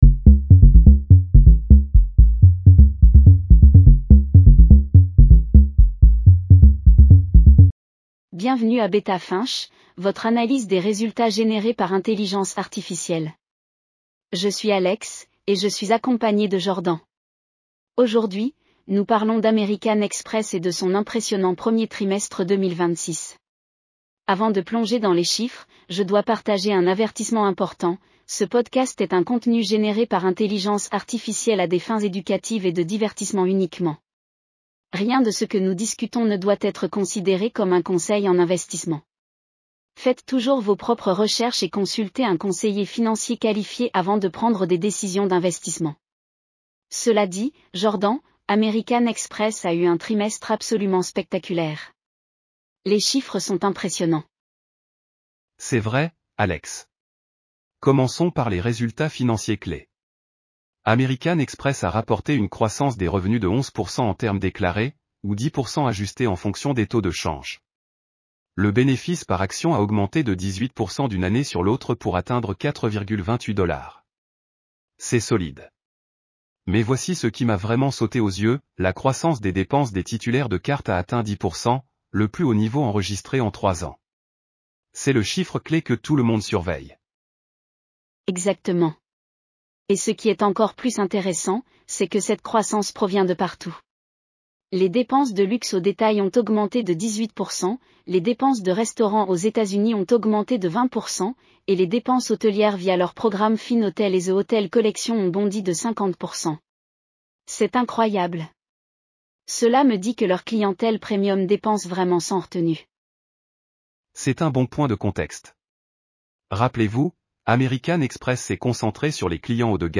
American Express Q1 2026 earnings call breakdown.